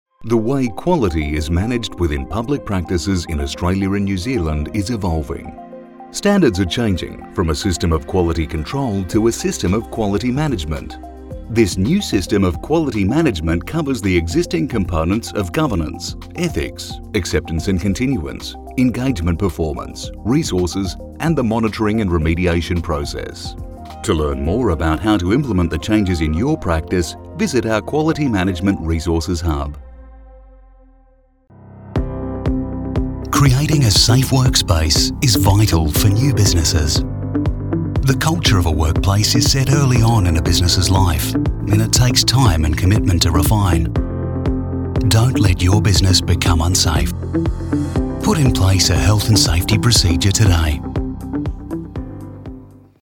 Male
English (Australian), English (Neutral - Mid Trans Atlantic)
Adult (30-50), Older Sound (50+)
Corporate
Training Video & Corporate Ad
0619Corporate_Demo.mp3